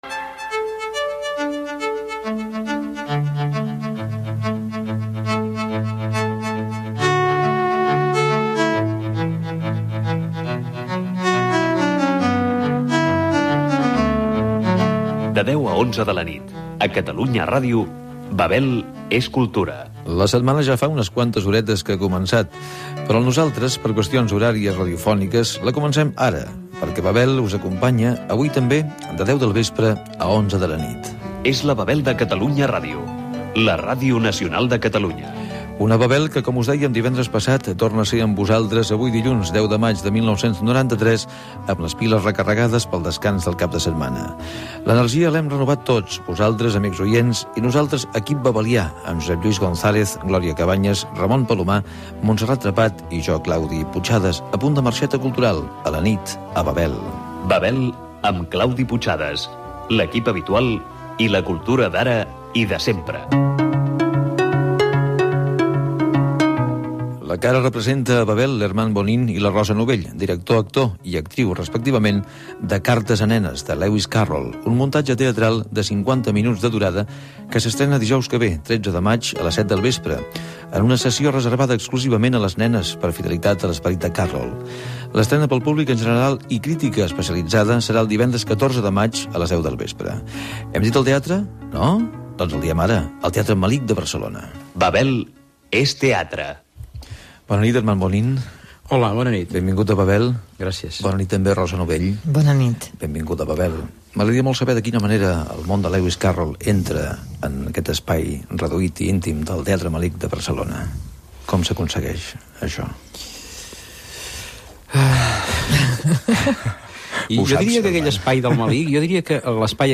Data, presentació, equip, indicatiu del programa, estrena de l'obra de teatre "Cartes a nenes" amb entrevista a l'actriu Rosa Novell i el director Hermannn Bonnín Gènere radiofònic Cultura